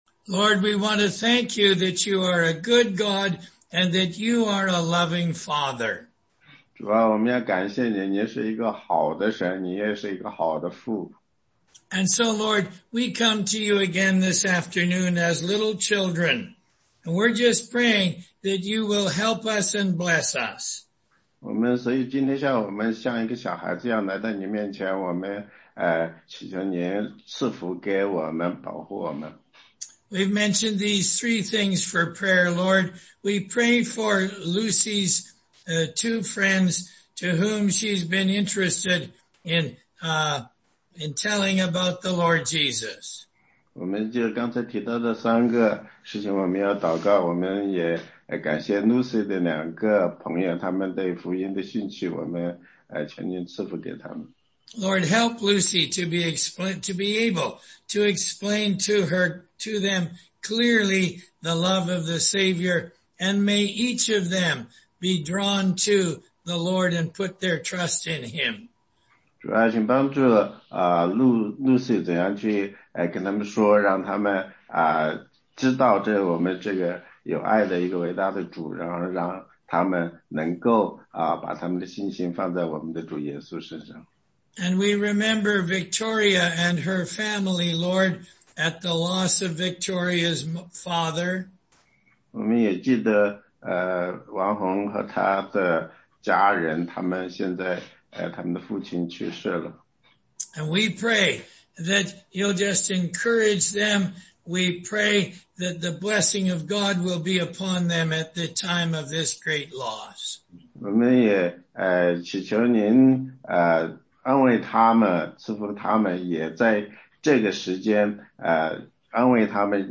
16街讲道录音 - 以弗所书4章17-32节：脱去旧人，穿上新人
答疑课程